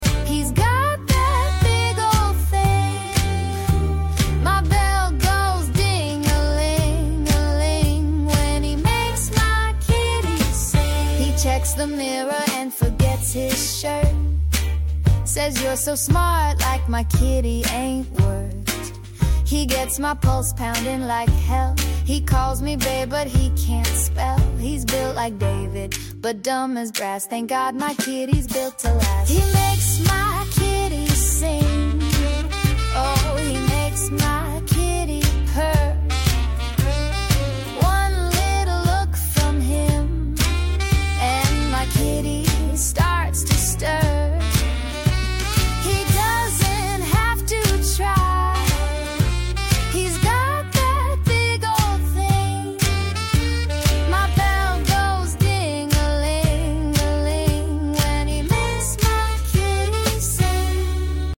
AI Generated 50s Music Unhinged Obscure Vinyl Parody Song